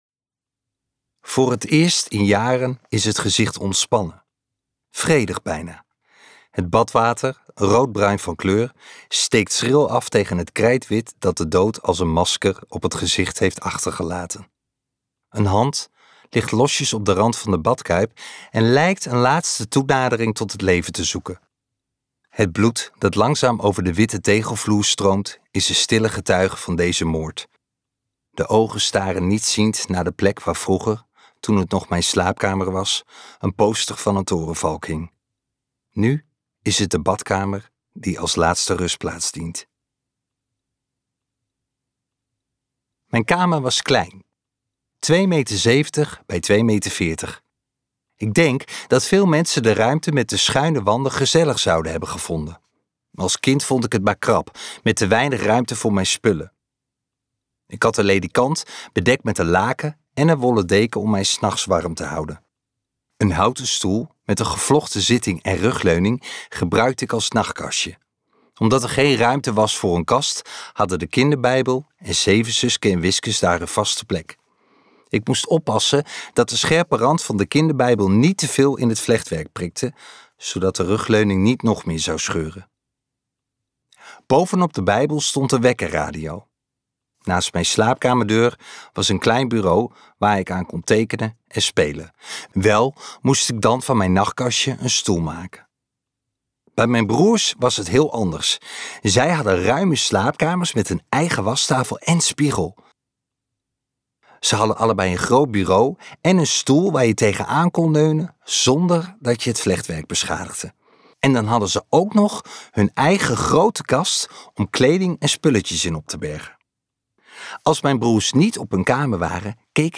Ambo|Anthos uitgevers - Winterwater luisterboek